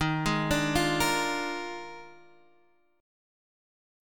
D#M7sus2sus4 chord